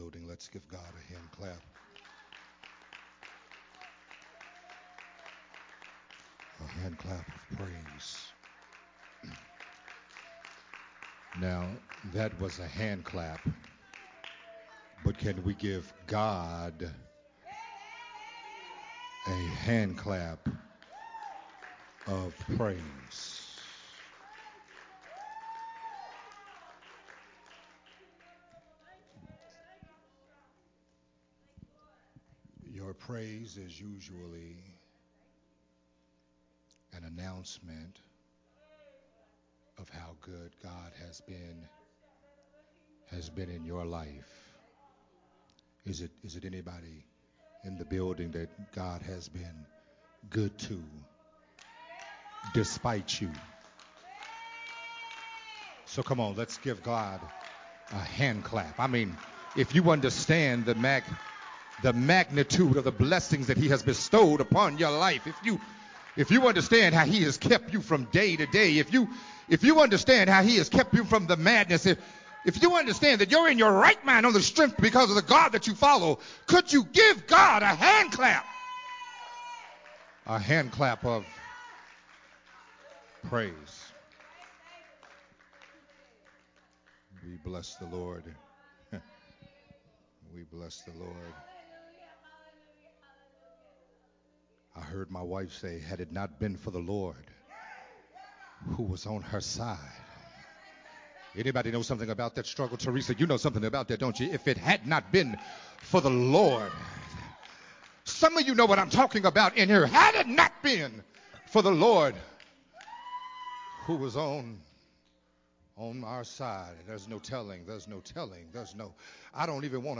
sermon series
recorded at Unity Worship Center